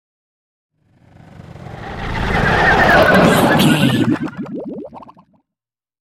Scifi whoosh pass by bubbles
Sound Effects
bouncy
futuristic
high tech
pass by